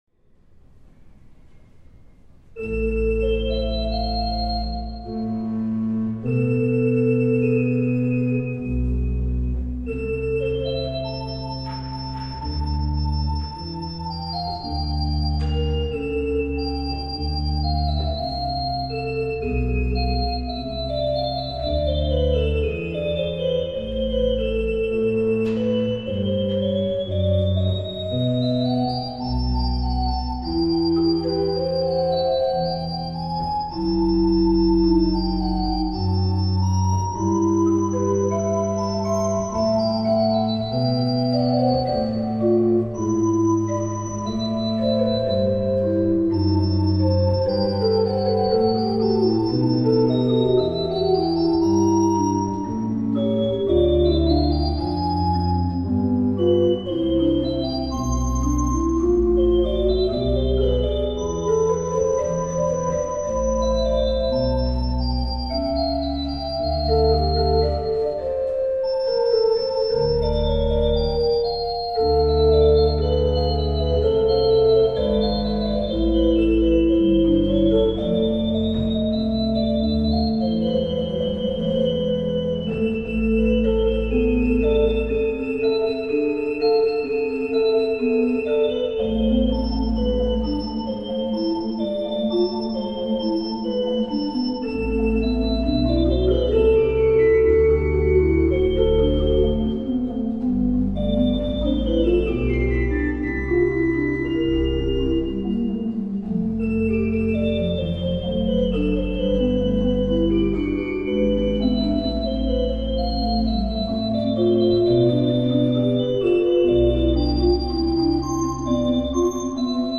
Auch heute hören wir Orgelmusik
in St. Josef eingespielt wurde.
Johann Sebastian Bach – Triosonate in C BWV 529 – Largo | Orgel